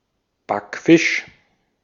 Ääntäminen
Ääntäminen Tuntematon aksentti: IPA: [ˈbakˌfɪʃ] Haettu sana löytyi näillä lähdekielillä: saksa Käännös Substantiivit 1. baked or fried fish 2. teenage girl 3. backfisch Artikkeli: der .